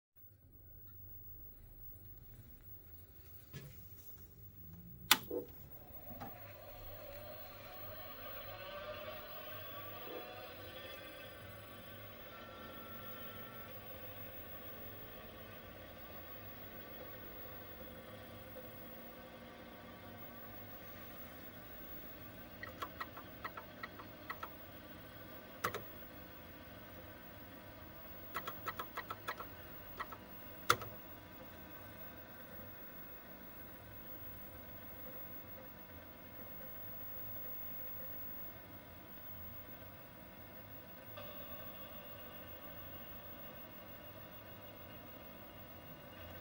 The sound of a dying sound effects free download
The sound of a dying harddisk which is still capable of booting those Kaypro 10!